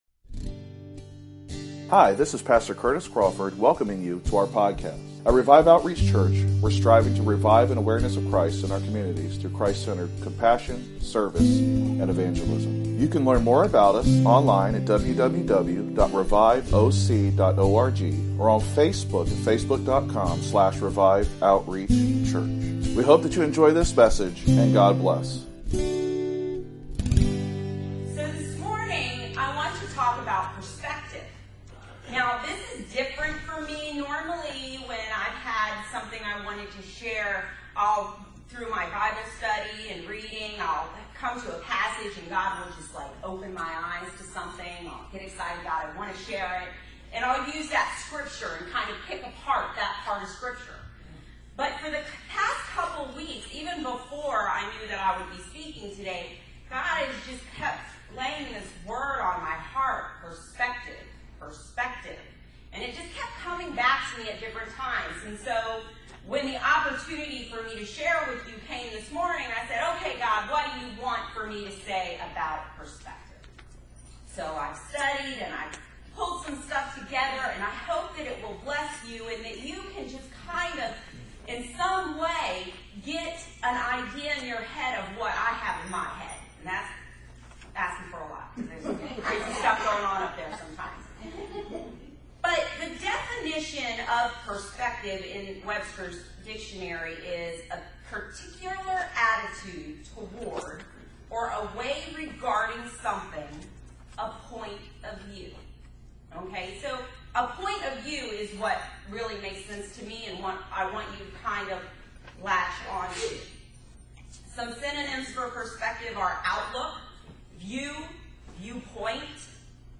15 May '18 The Holy Spirit Sermon Series Posted on May 15, 2018 in Devotional | 15 May '18 Enable javascript to use sermon-player, or use the subscribe-podcast links below.